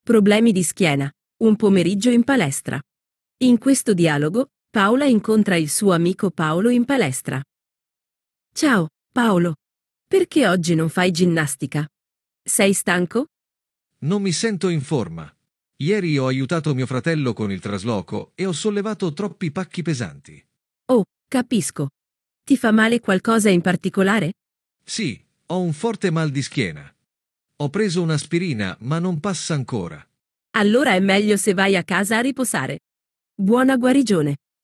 Dialogo 1